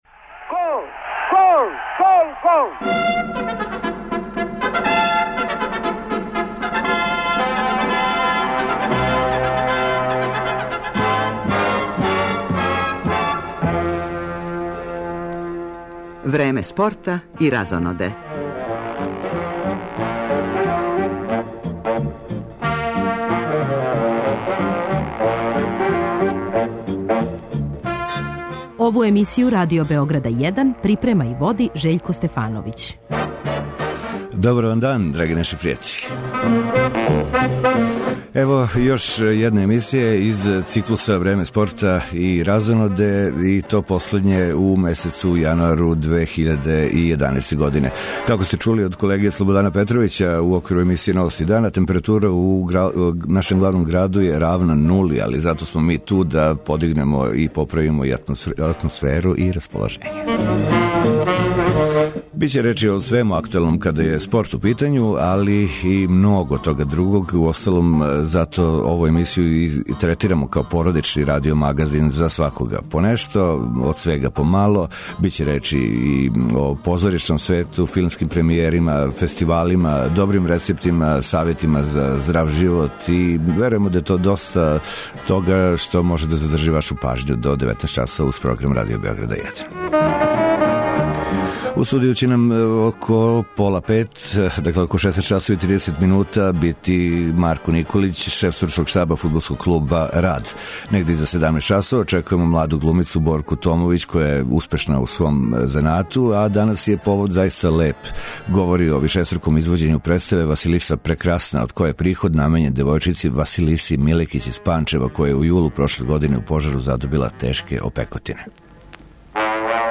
Много гостију у студију, доста занимљивих тема из свих области јавног живота, добра музика - и данас су саставни део породичног радио магазина Време спорта и разоноде. Тениски турнир Аустралија опен у центру пажње, одмах за њим и осврт на учешће рукометаша на СП у Шведској, кошаркаша Партизана у Лиги шампиона ,а пратићемо и кретање резултата на утакмицама значајнијих европских фудбалских шампионата.